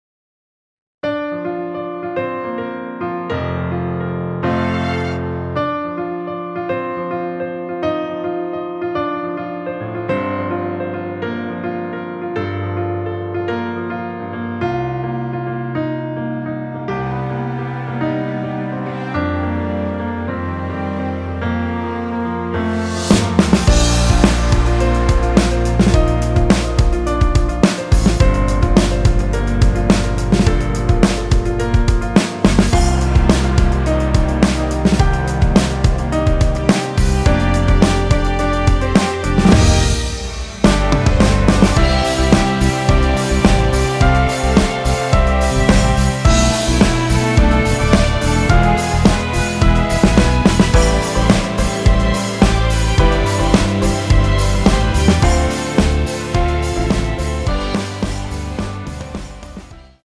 전주 없이 시작 하는곡이라 노래 하시기 좋게 2마디 전주 만들어 놓았습니다.(약 5초쯤 노래 시작)
Bb
앞부분30초, 뒷부분30초씩 편집해서 올려 드리고 있습니다.
중간에 음이 끈어지고 다시 나오는 이유는